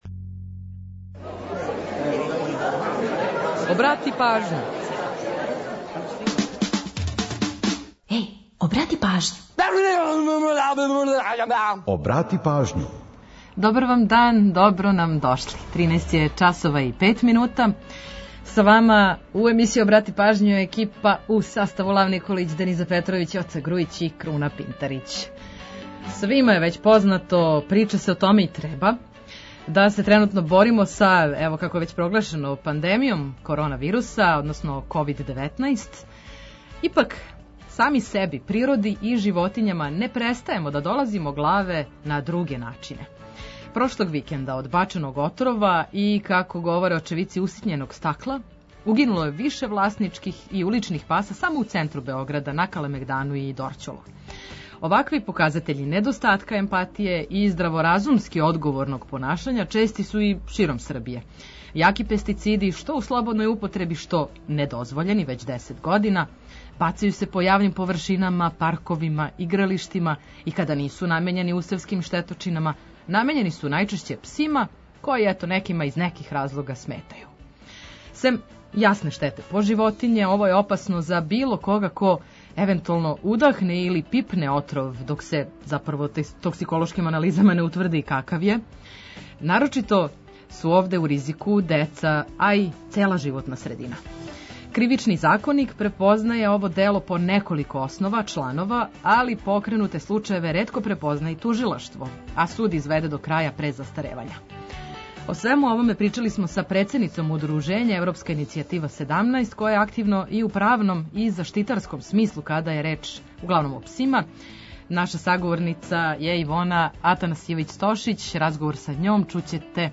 Ту је и пола сата резервисаних само за нумере из Србије и региона.